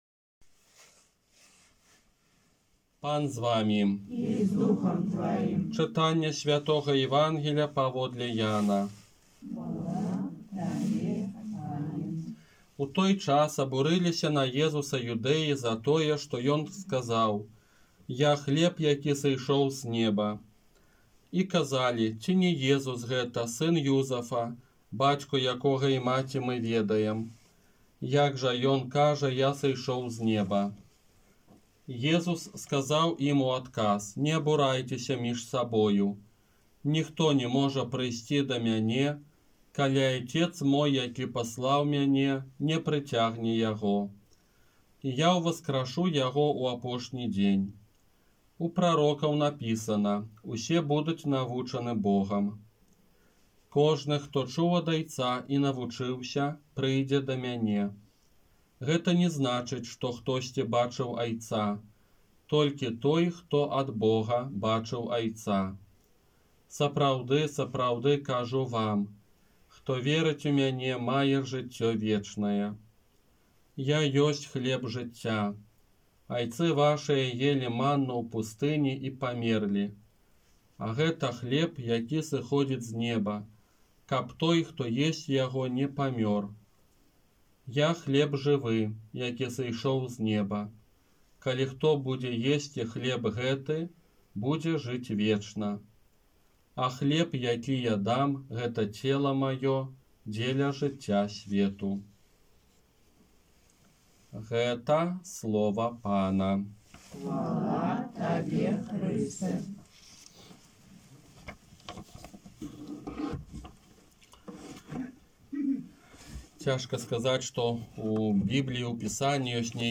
ОРША - ПАРАФІЯ СВЯТОГА ЯЗЭПА
Казанне на дзевятнаццатую звычайную нядзелю